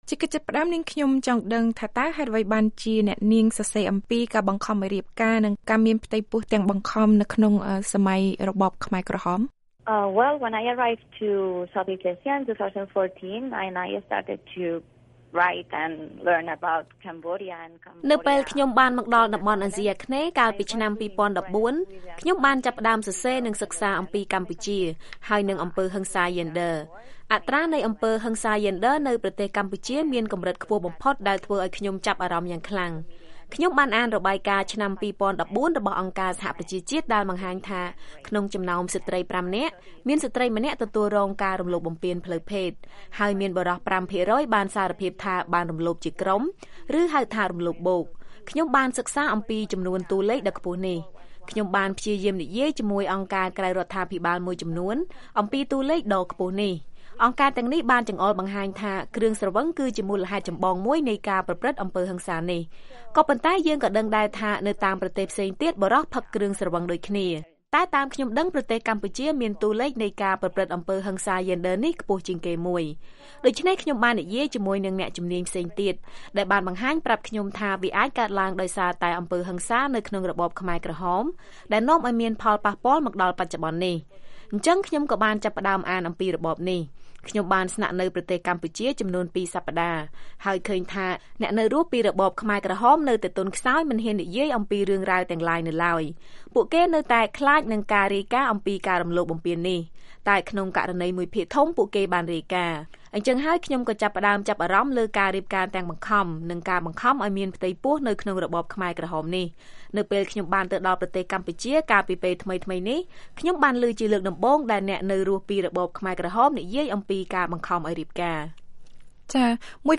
បទសម្ភាសន៍ VOA៖ ឧក្រិដ្ឋកម្មនៃការបង្ខំឲ្យរៀបការ និងការបង្ខំឲ្យមានផ្ទៃពោះក្នុងរបបខ្មែរក្រហមមិនត្រូវបាន«‍យកចិត្តទុកដាក់»